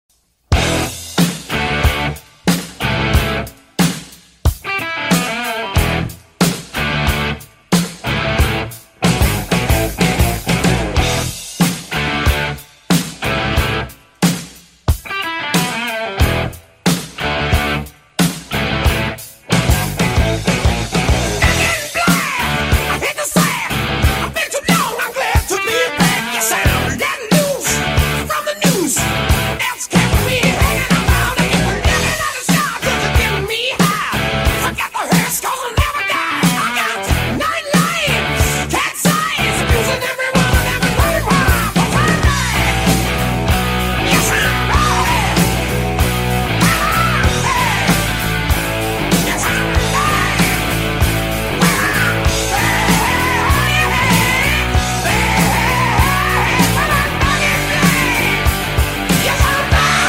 Fast Climb F35 Sound Effects Free Download